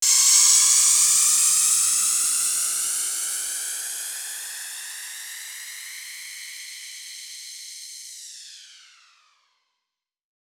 TC3Transition3.wav